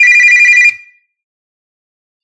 Phone.ogg